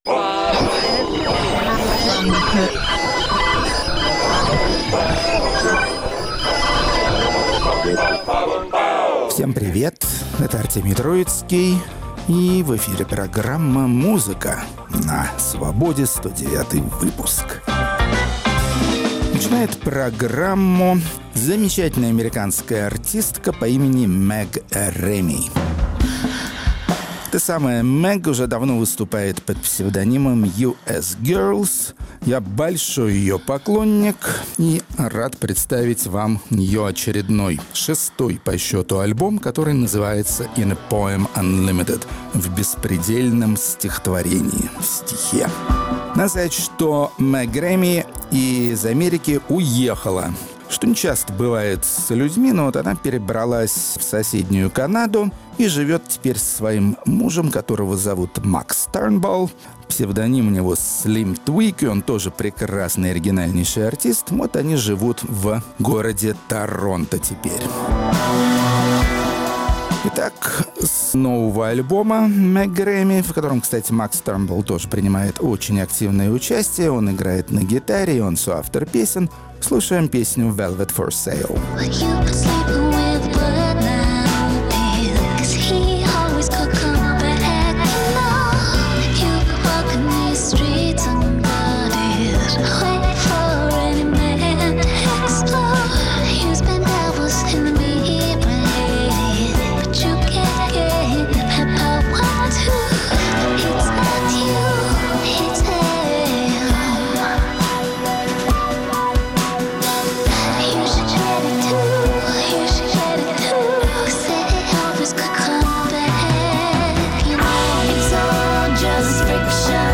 Музыка на Свободе Исполнители "гаражного рока" из Новой Зеландии, таких вы еще не слышали. Рок-критик Артемий Троицкий свидетельствует, что он уважает не только сложные, но и простые ритмы, если они громкие и энергичные.